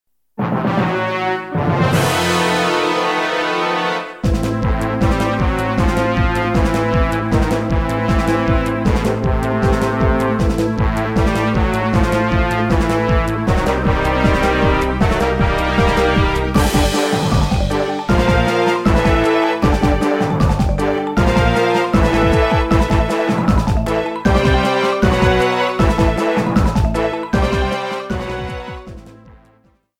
Fair use music sample
Reduced length to 30 seconds, with fadout.